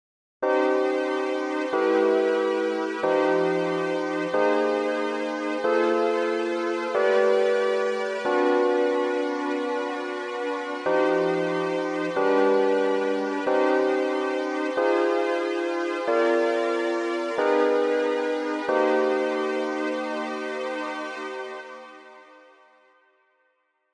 響きは「すっきり・あいまい」という感じです。
という訳で、以下の実施例は上三声に接触する位置(付加音に近い形）を織り交ぜています。